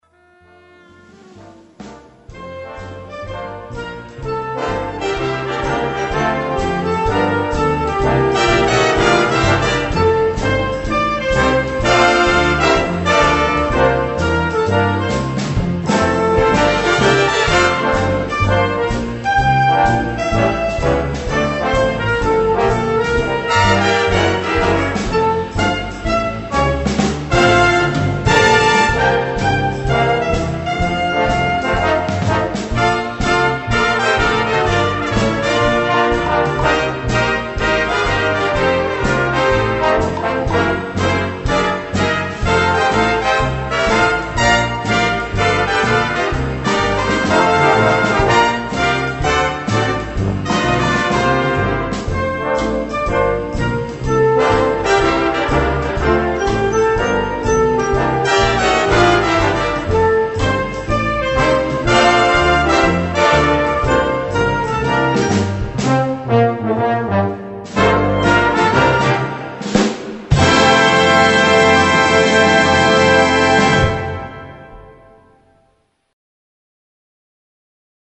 Gattung: Weihnachtsmusik (Swing)
Besetzung: Blasorchester